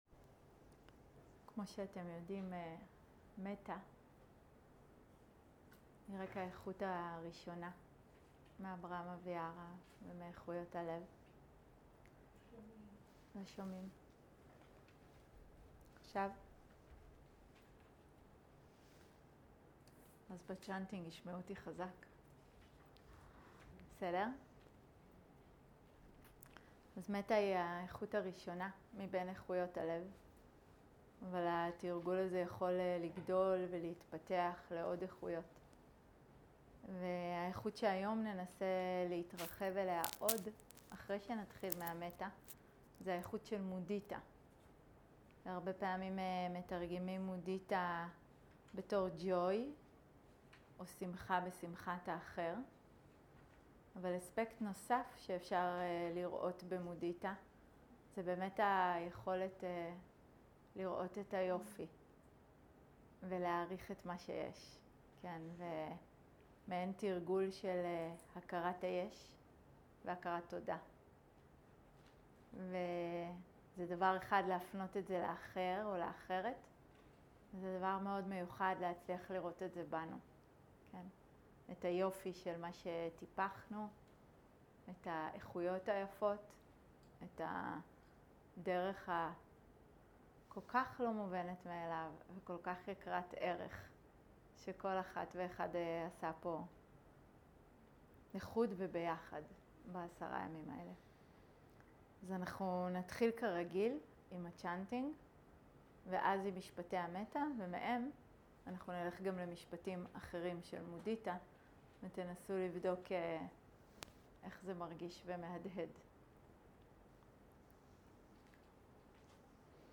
מטא ומודיטה + צאנטינג
Dharma type: Guided meditation שפת ההקלטה